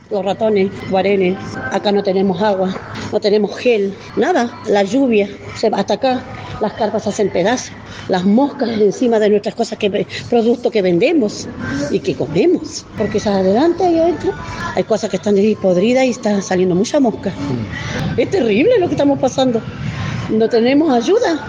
“Ratones, no tenemos agua, no tenemos nada, la lluvia, las carpas se hacen pedazos, las moscas encima de nuestros productos que vendemos y que comemos (…)”, indicó la comerciante.